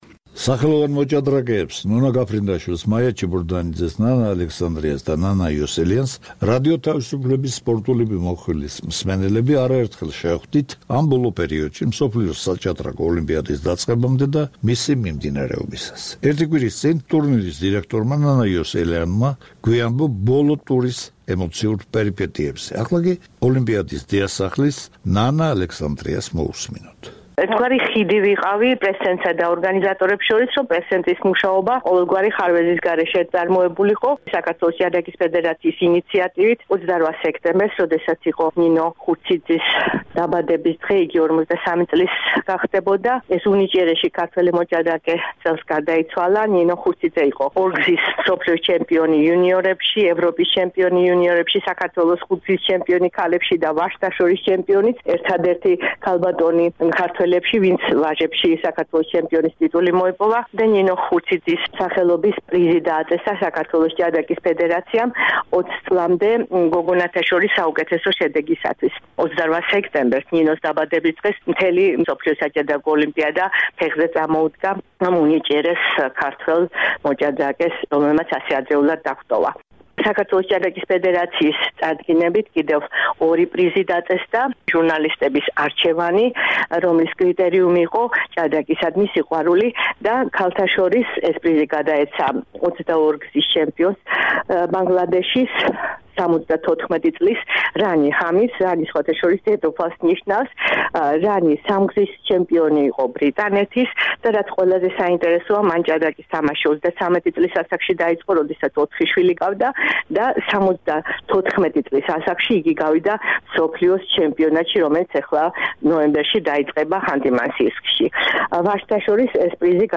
მსოფლიოს 43-ე საჭადრაკო ოლიმპიადის შედეგებზე საუბრობენ დიდოსტატები